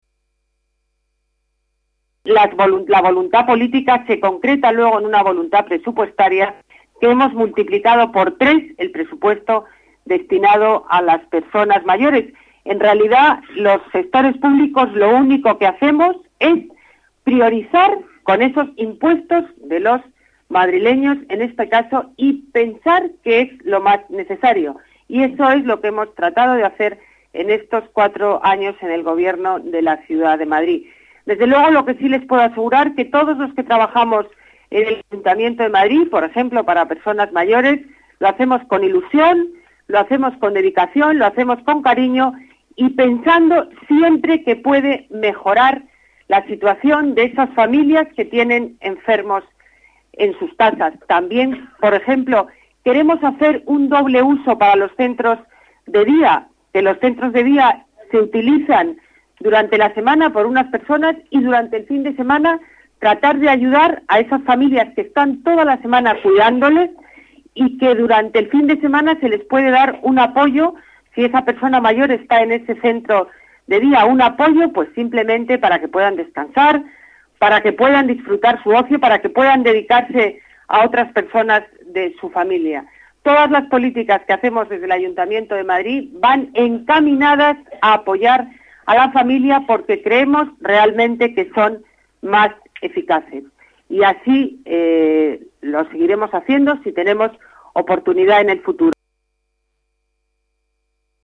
Nueva ventana:Declaraciones de Ana Botella en la inauguración de la Residencia y Centro de Día para enfermos de Alzheimer "Margarita Retuerto"